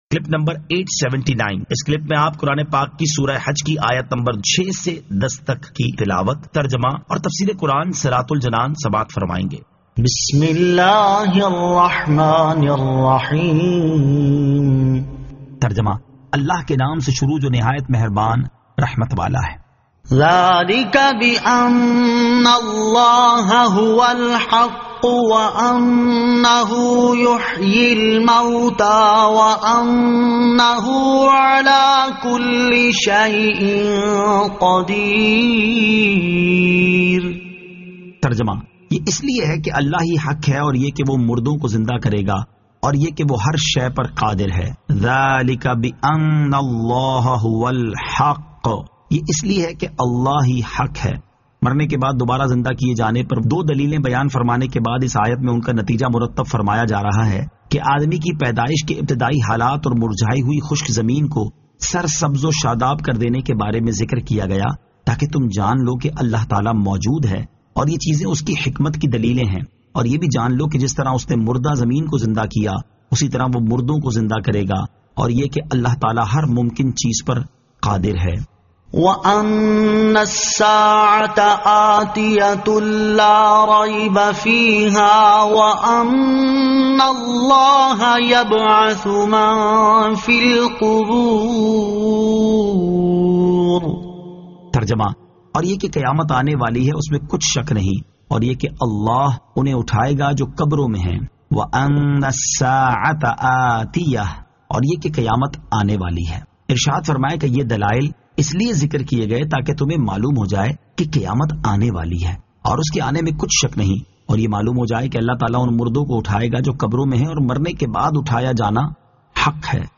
Surah Al-Hajj 06 To 10 Tilawat , Tarjama , Tafseer
2022 MP3 MP4 MP4 Share سُورَۃُ الْحَجِّ آیت 06 تا 10 تلاوت ، ترجمہ ، تفسیر ۔